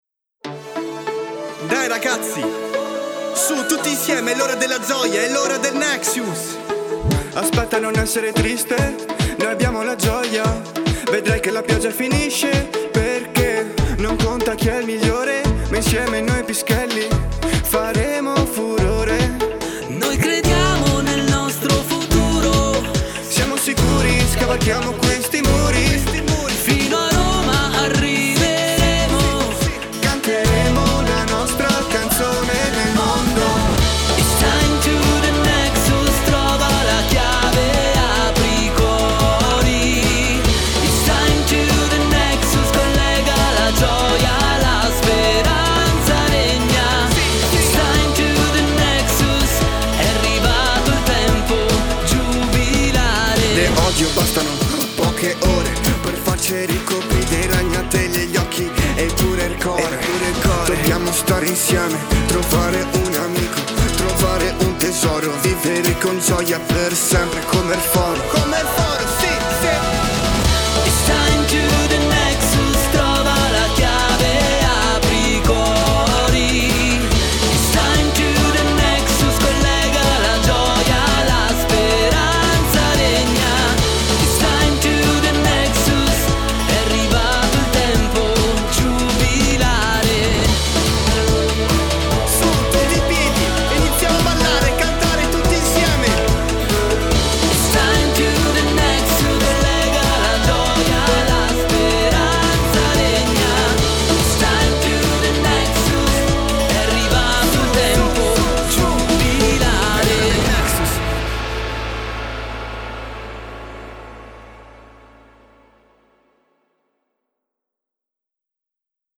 INNO File audio INNO della canzone